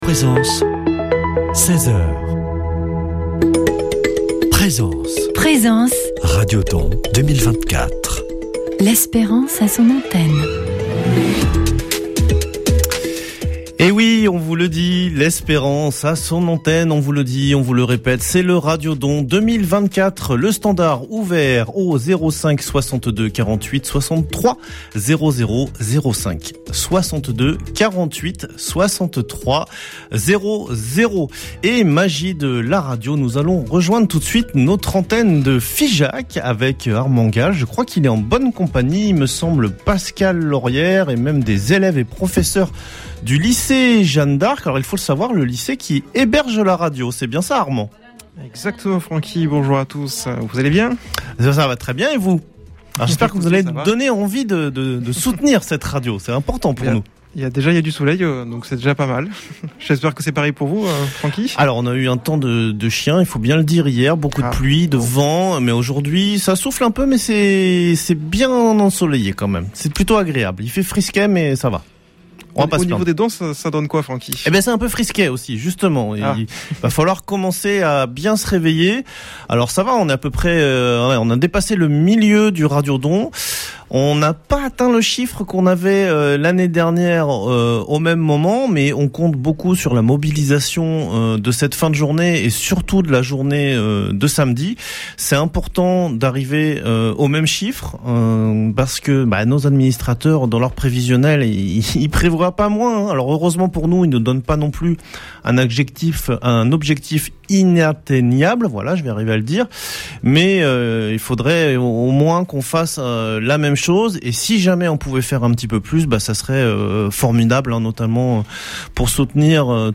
Voici laTable Ronde en direct de Figeac du Vendredi